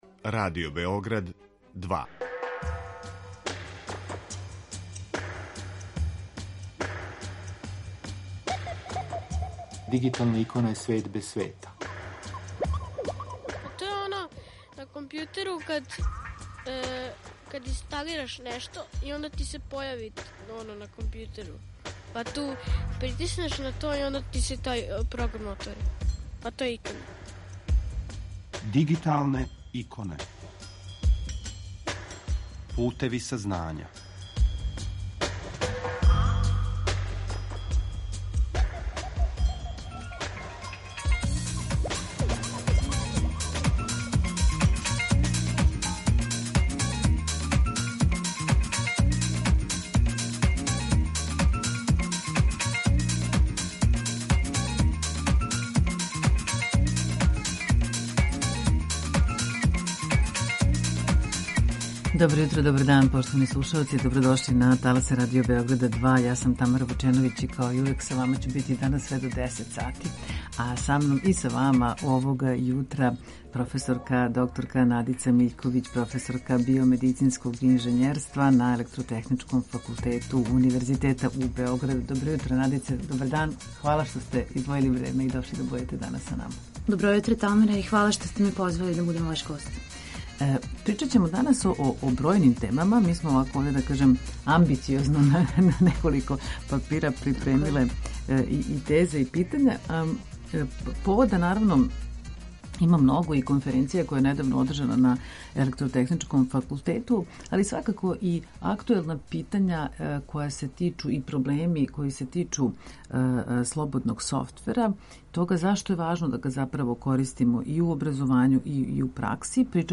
Са нама уживо овога јутра